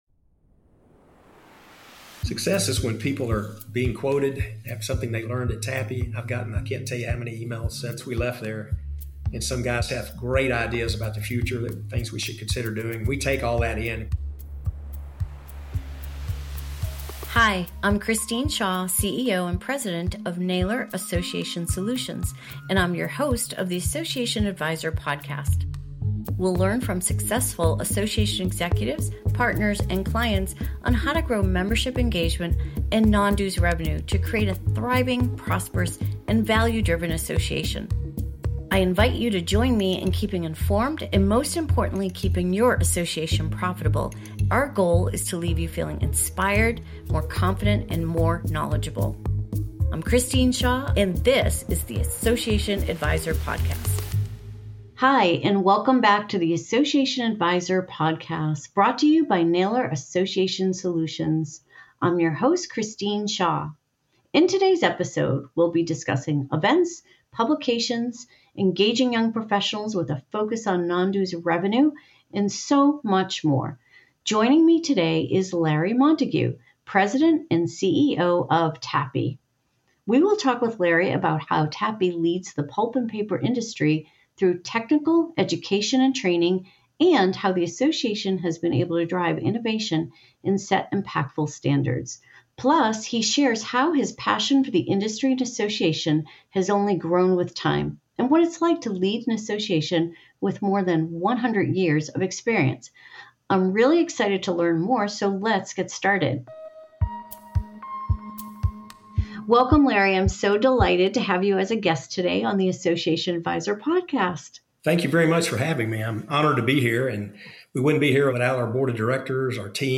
This 27-minute interview touches on the sustainability of our industry and how we are positioning ourselves to be the industry of choice for the next generation.